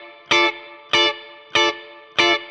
DD_StratChop_95-Dmin.wav